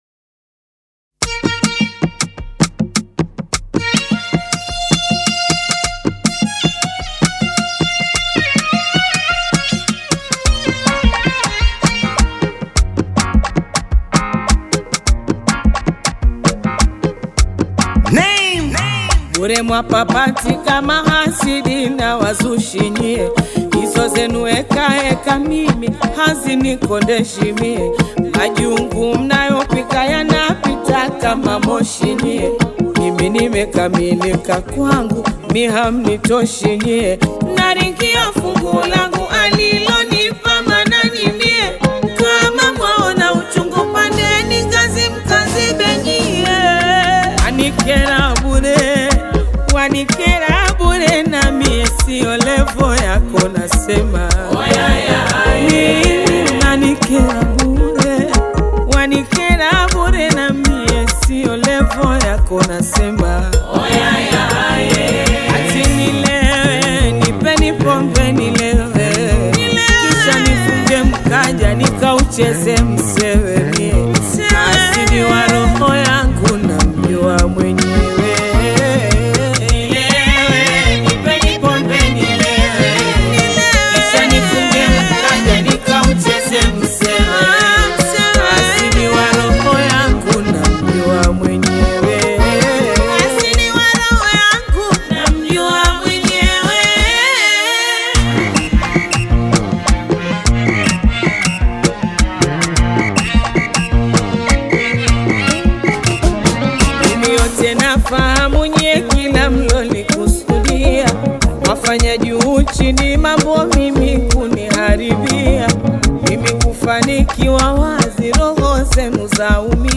Taarabu